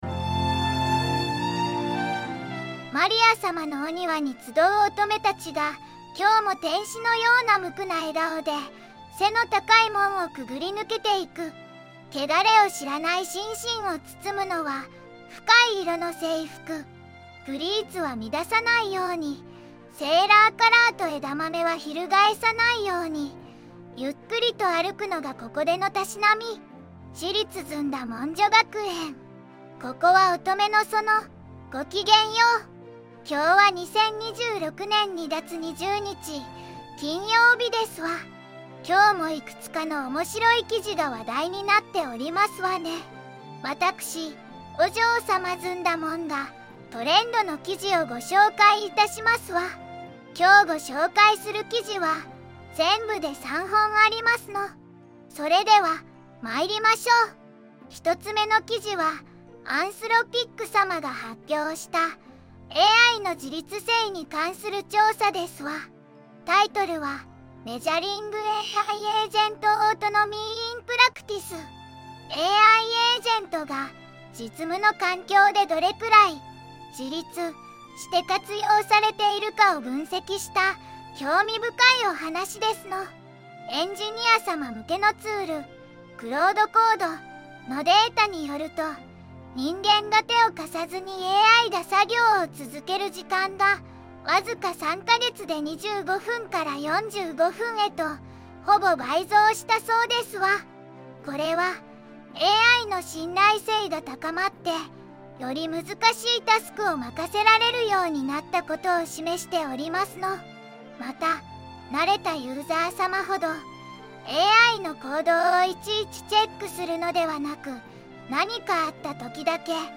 お嬢様ずんだもん
VOICEVOX:ずんだもん